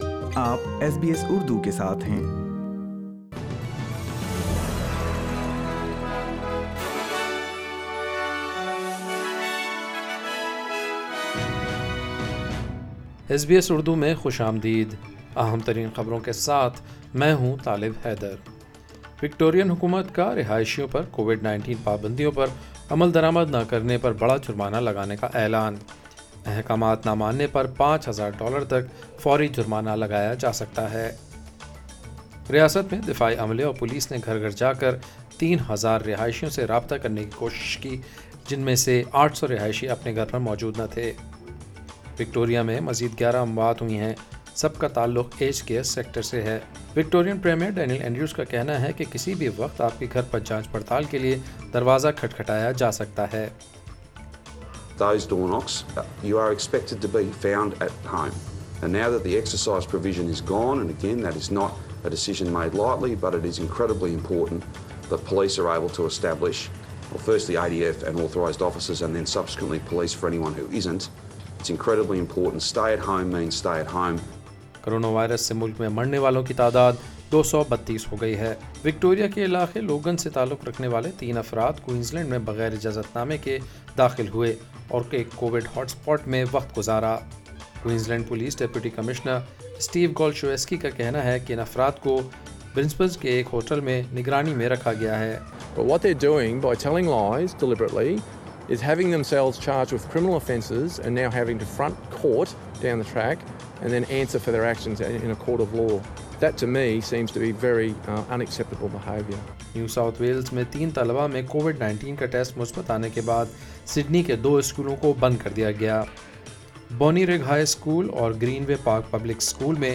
daily_news_4.8.20.mp3